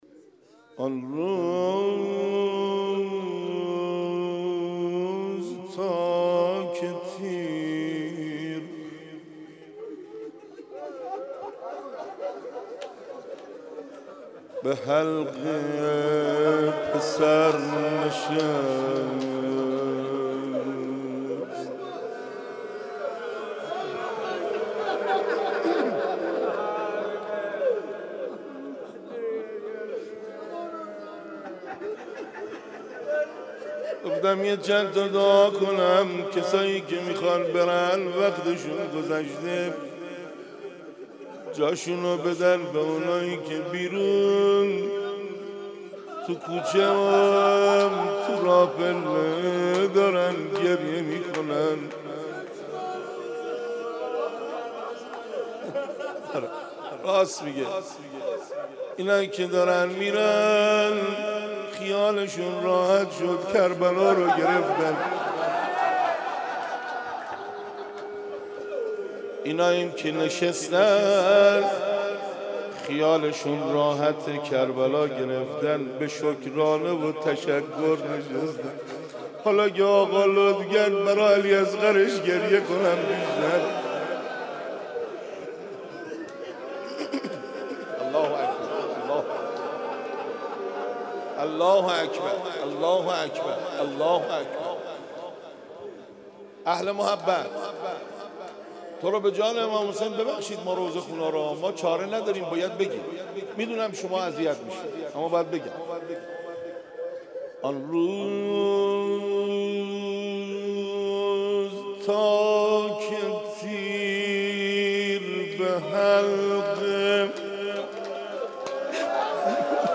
مناسبت : شب دوم محرم
قالب : روضه